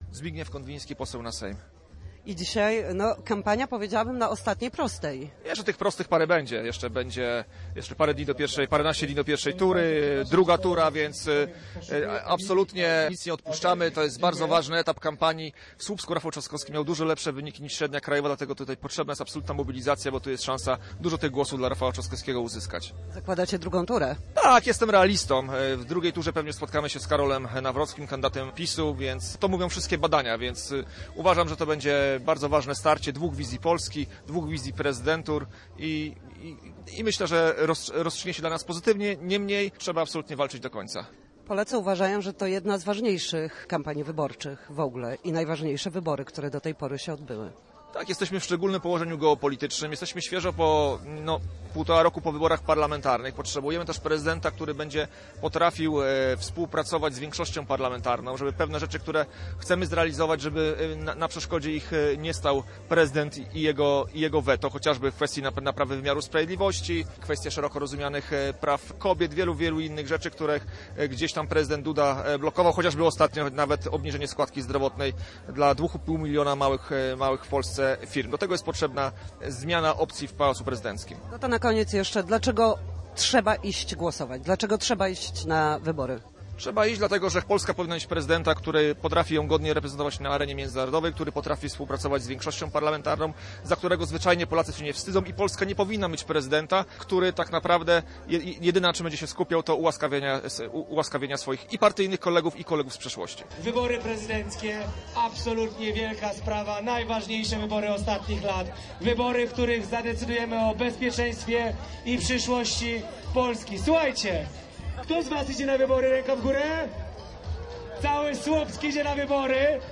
Kilkaset osób przyszło na spotkanie z Rafałem Trzaskowskim w Słupsku w piątek, 2 maja. Kandydat na urząd prezydenta podczas rozmowy z mieszkańcami poruszył kilka kwestii dotyczących rozwoju mniejszych miast, dostępu do opieki zdrowotnej oraz zapewnienia wysokiej jakości edukacji.